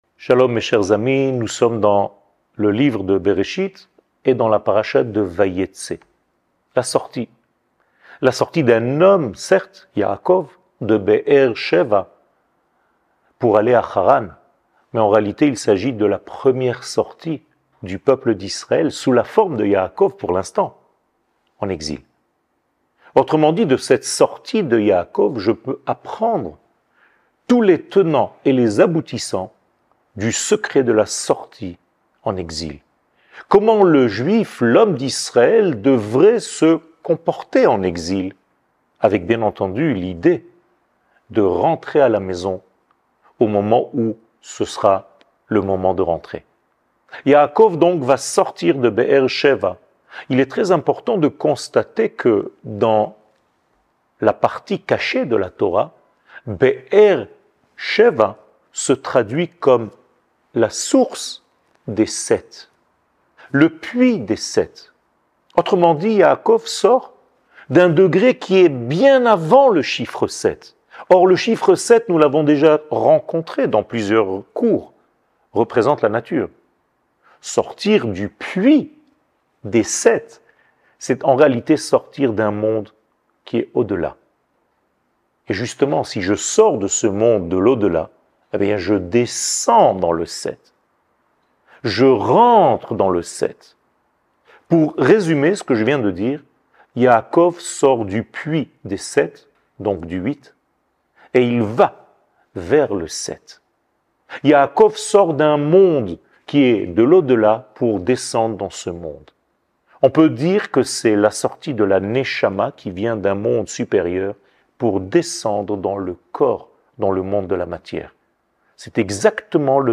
קטגוריה paracha Vayetse 00:05:51 paracha Vayetse שיעור מ 29 נובמבר 2022 05MIN הורדה בקובץ אודיו MP3